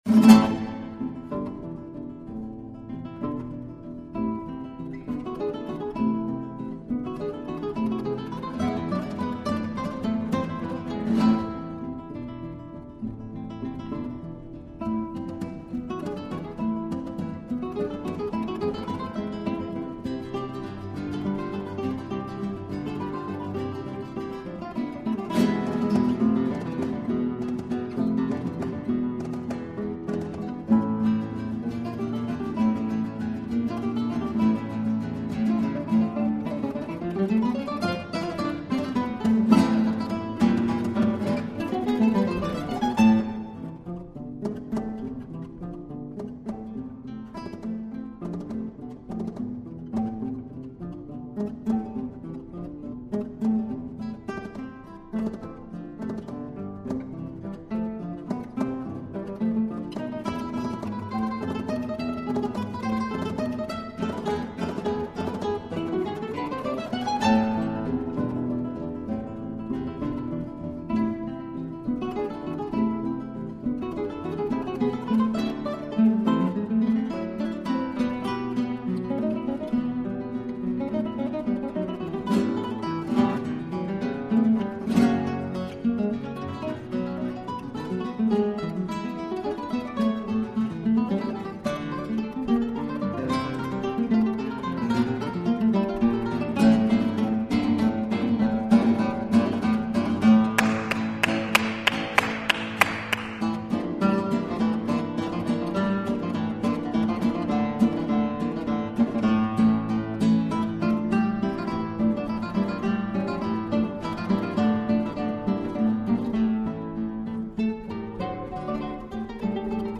0259-吉他名曲西班牙舞曲.mp3